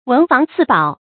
注音：ㄨㄣˊ ㄈㄤˊ ㄙㄧˋ ㄅㄠˇ
文房四寶的讀法